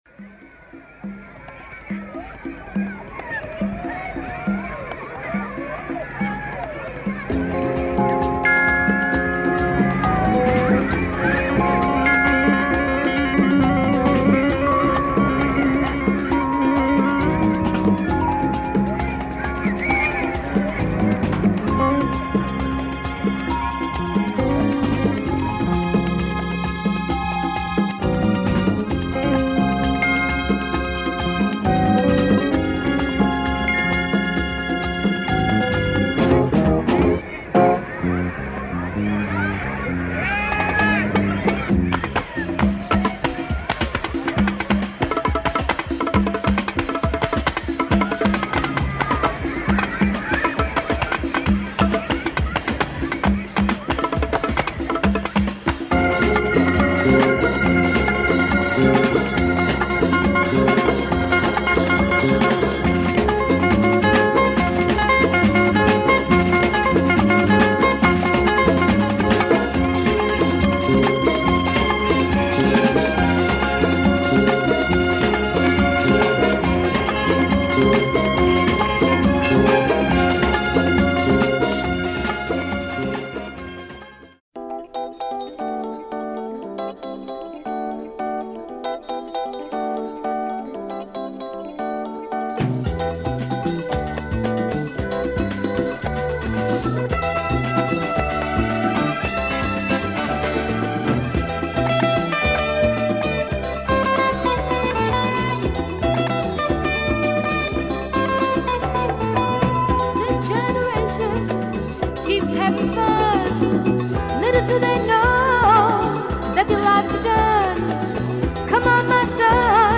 Excellent latin soul
A pinch of psych and lots of groove on Fender Rhodes !